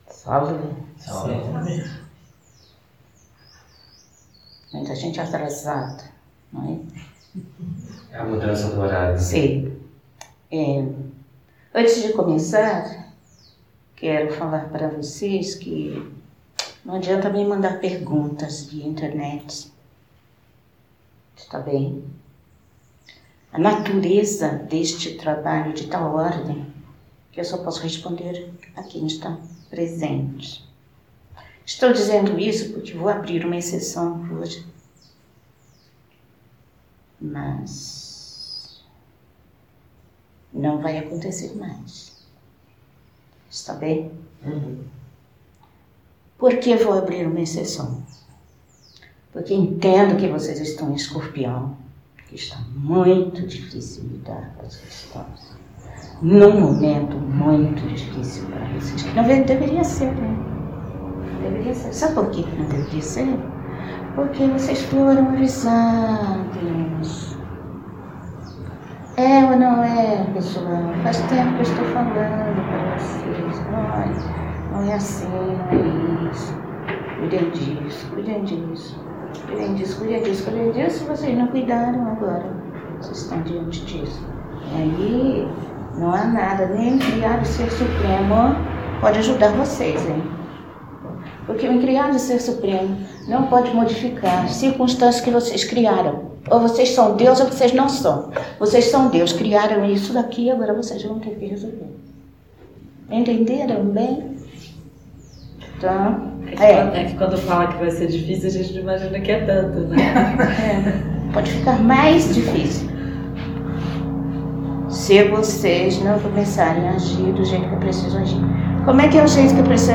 Perguntas e respostas